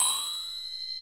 powerTimeSlower.wav